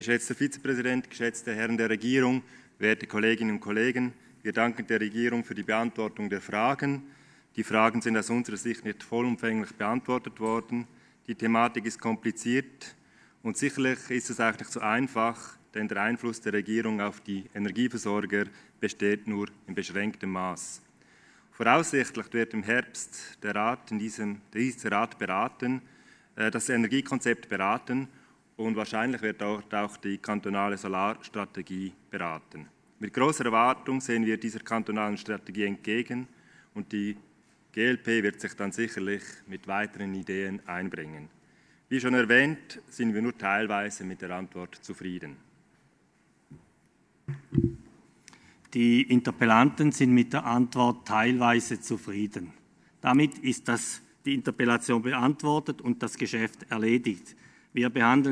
19.5.2020Wortmeldung
Session des Kantonsrates vom 18. bis 20. Mai 2020, Aufräumsession